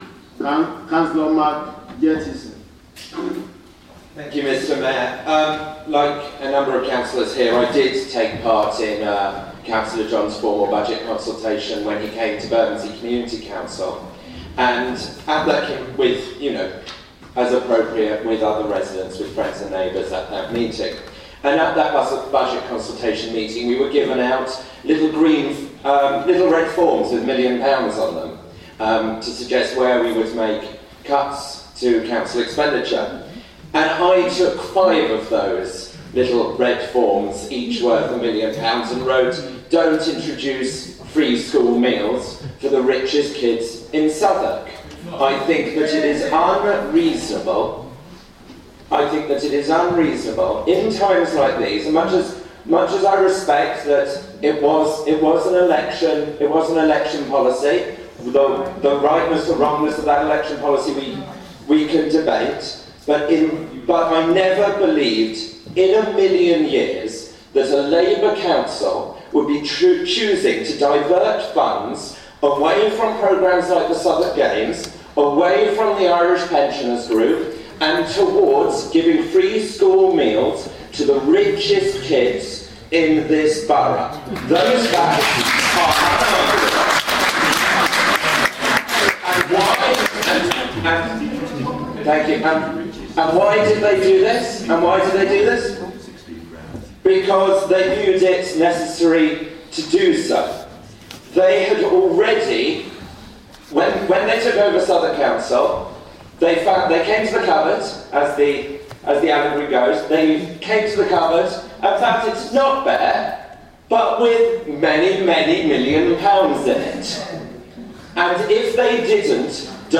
Cllr Mark Gettleson's speech in Southwark's budget debate